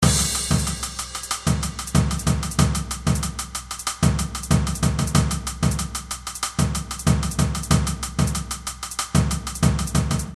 SomeOtherPercussion.mp3